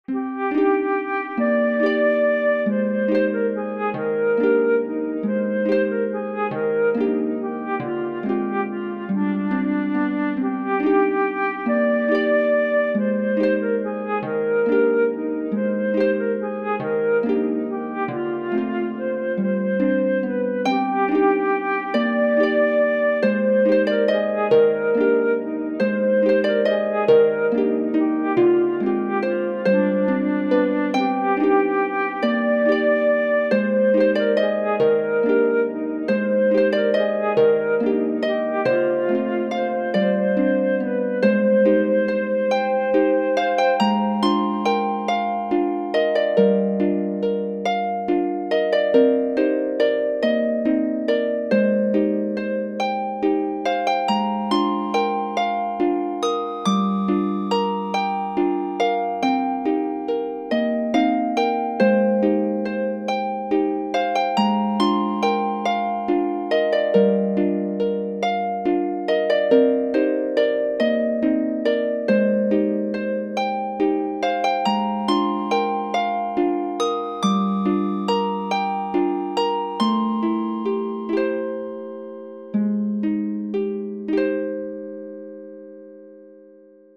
切なく寂しいメロディー　ハープとフルートのシンプルver.